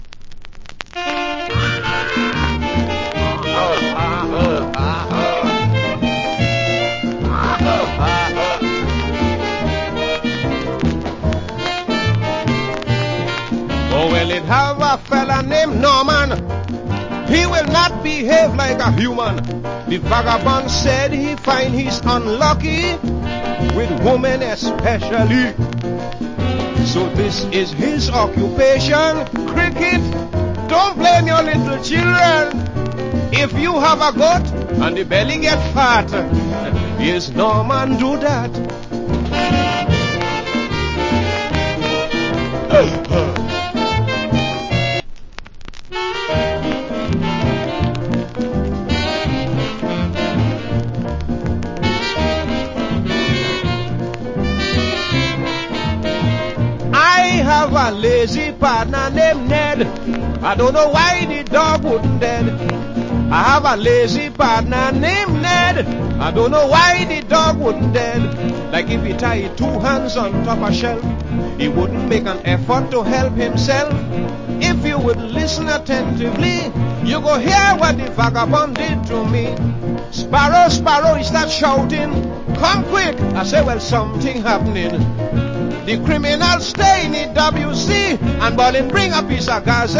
Nice Calyypso Vocal.